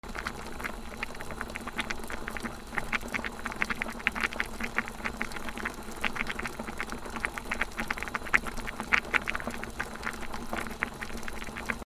なべ 沸騰
『グツグツ』